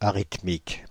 Ääntäminen
Synonyymit asynchrone Ääntäminen France (Île-de-France): IPA: /a.ʁit.mik/ Haettu sana löytyi näillä lähdekielillä: ranska Käännöksiä ei löytynyt valitulle kohdekielelle.